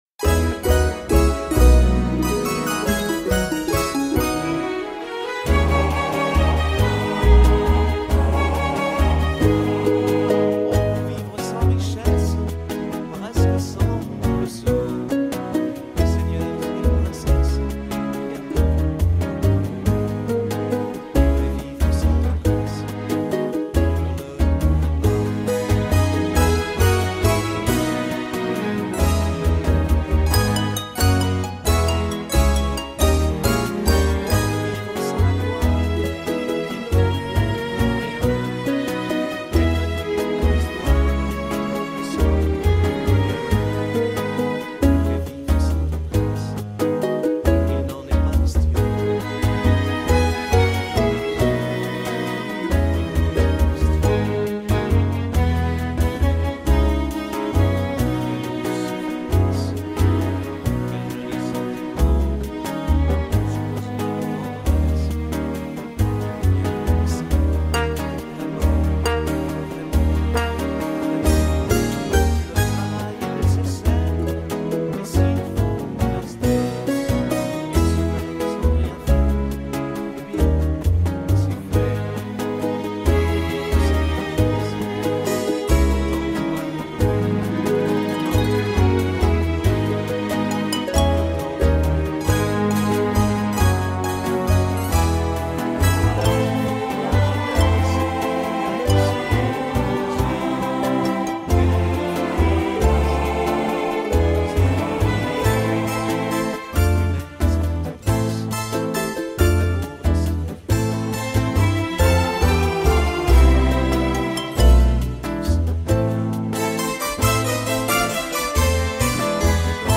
Version instrumentale :